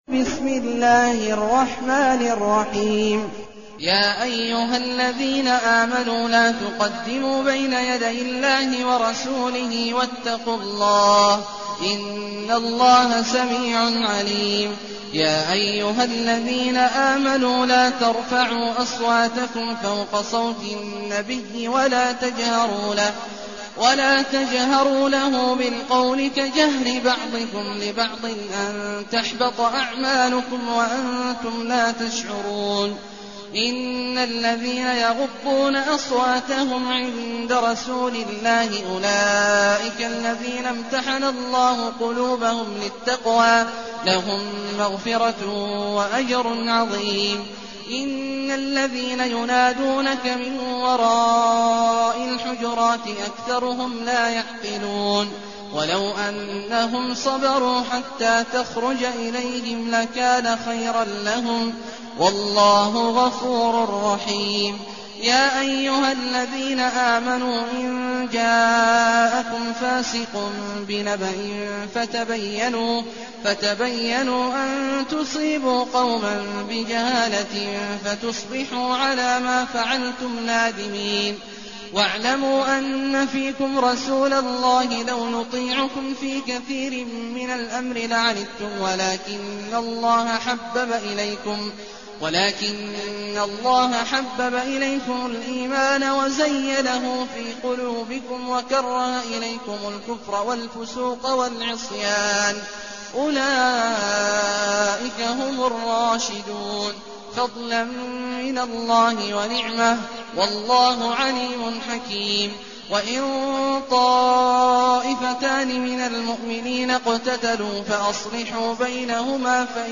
المكان: المسجد الحرام الشيخ: عبد الله عواد الجهني عبد الله عواد الجهني الحجرات